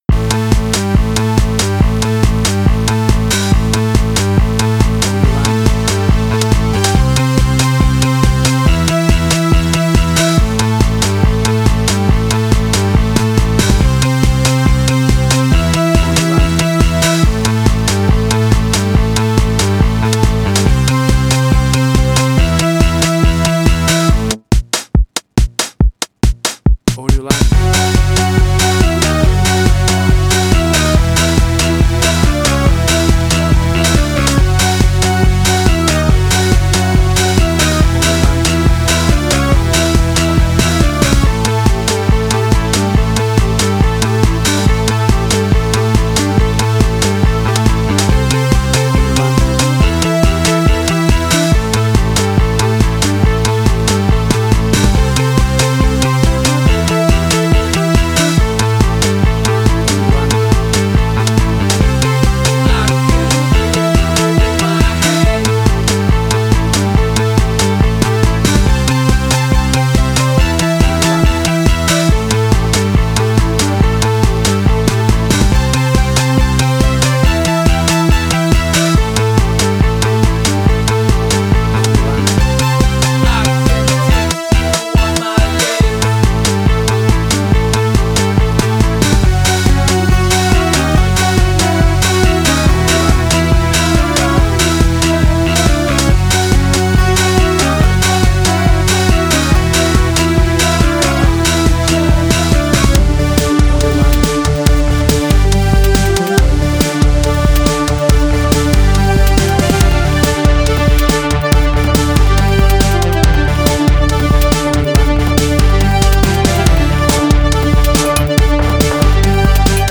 WAV Sample Rate: 16-Bit stereo, 44.1 kHz
Tempo (BPM): 140